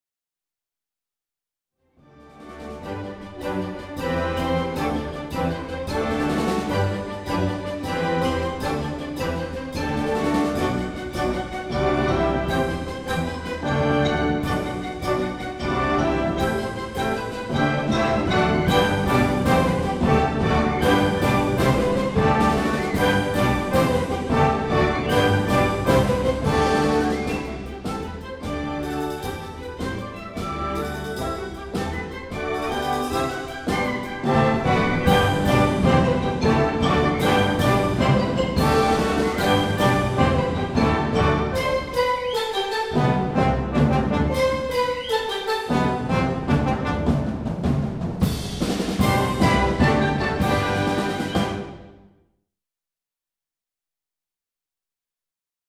a very tuneful energetic dance in mixed meter